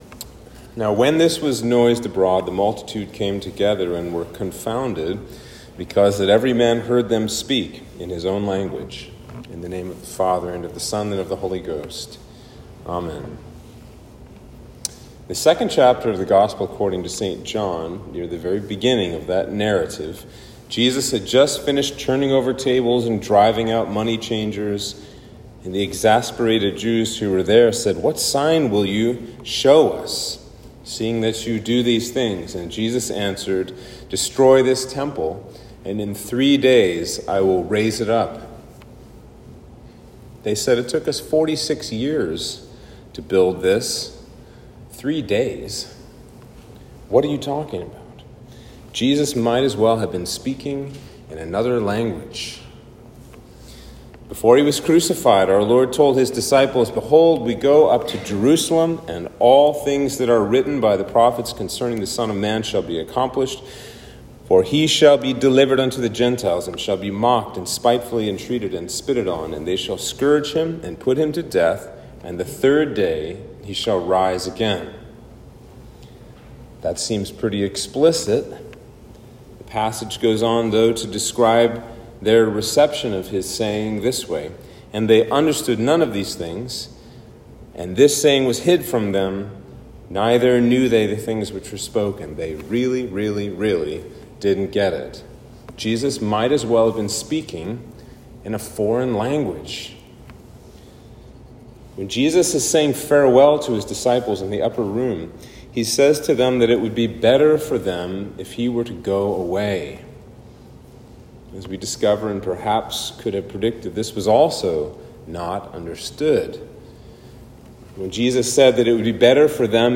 Sermon for Pentecost/Whitsunday